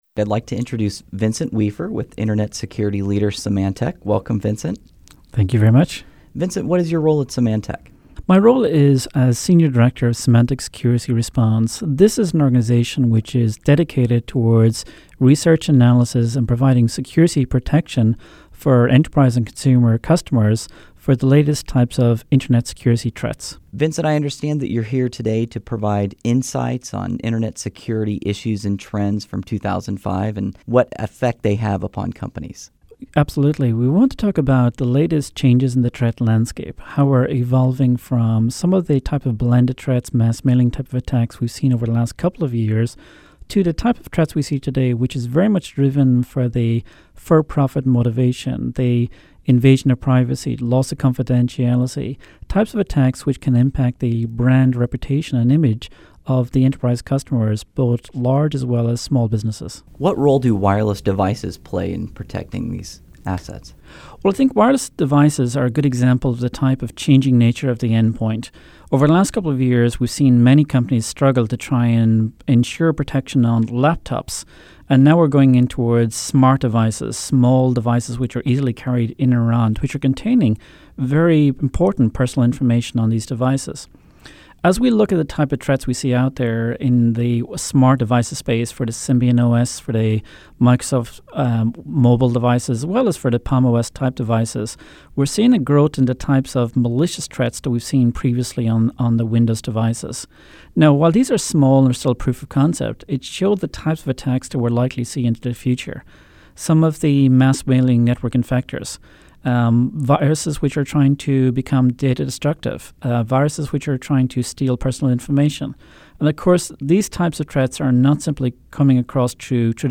This mp3 download is an interview
The interview was recorded in Hollywood, 7 November 2005, and distributed by Symantec.